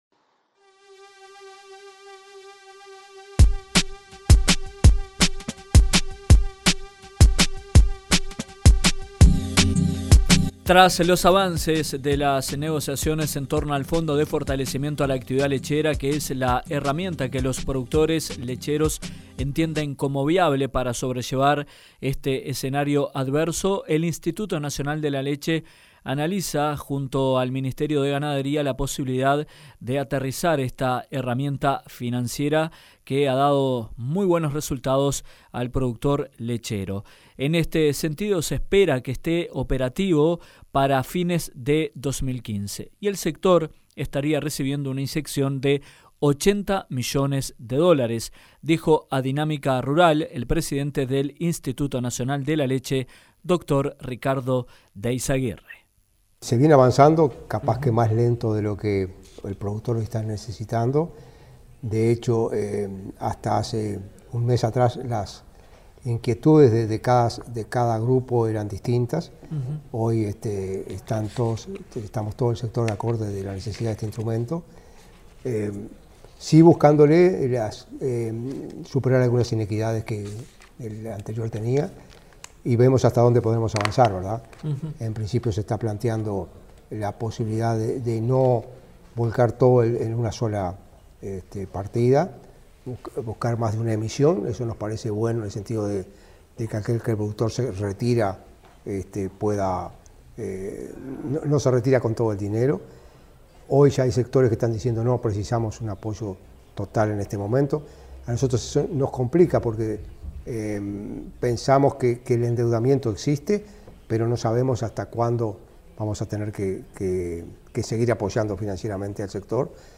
Según informó a Dinámica Rural el presidente de INALE, Ricardo De Izaguirre, dado el proceso de elaboración y de aprobación a fin de año el productor recibiría 80 millones de dólares. Mientras tanto se definen aspectos sustanciales para su puesta en práctica, agregó el titular del organismo lechero oficial.